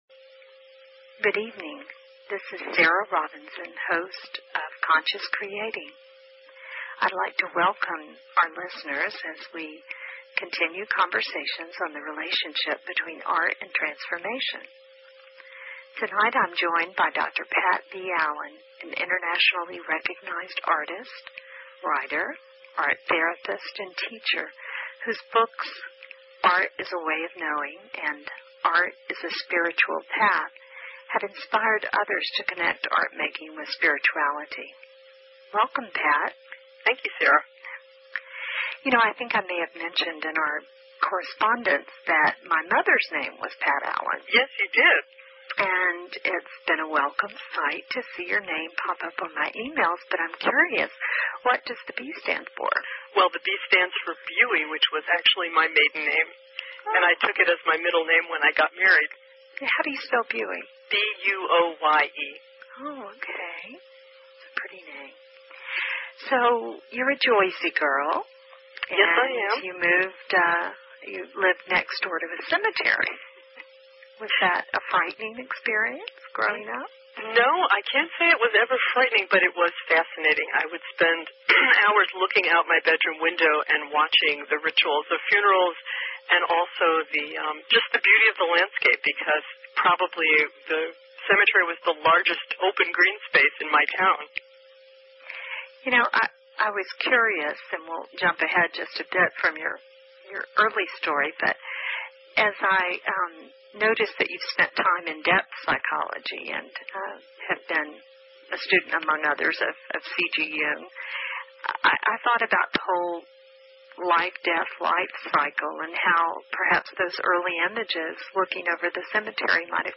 Talk Show Episode, Audio Podcast, Conscious Creating and Courtesy of BBS Radio on , show guests , about , categorized as